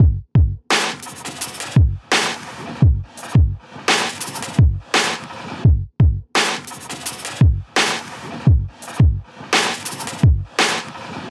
Бах бух бах